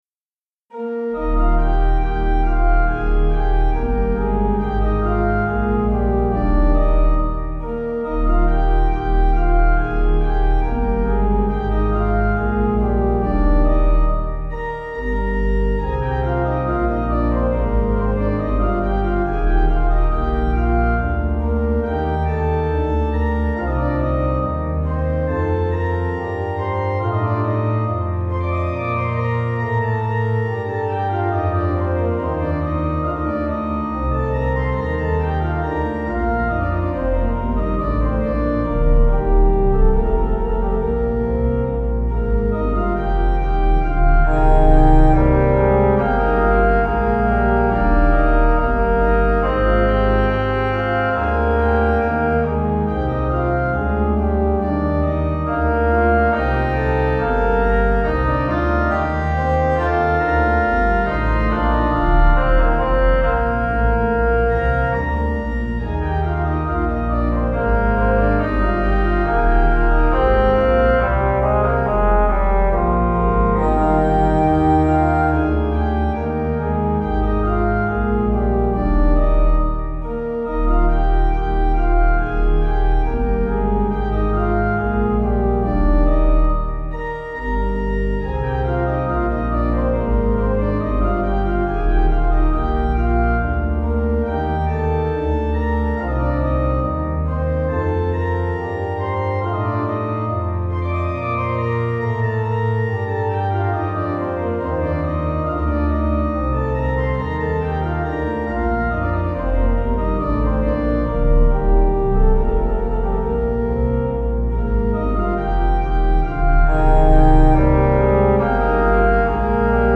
Organ
Easy Listening   Eb